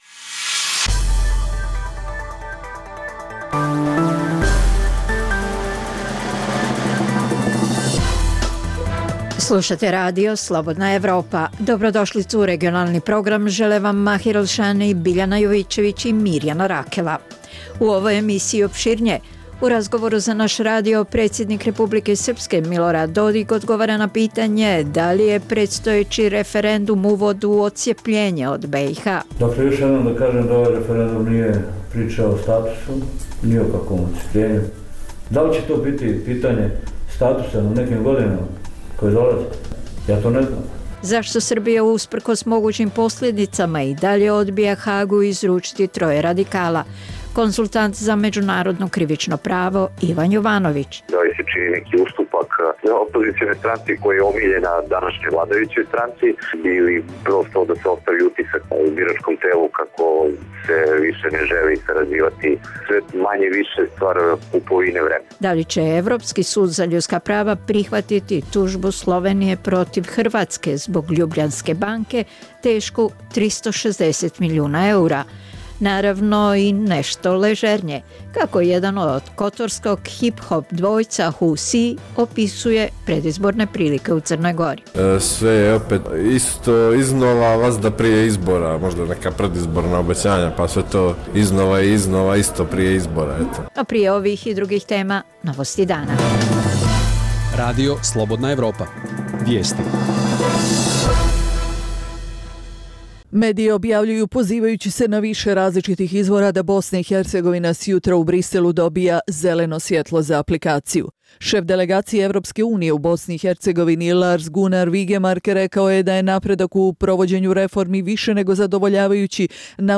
Reportaže iz svakodnevnog života ljudi su takođe sastavni dio “Dokumenata dana”.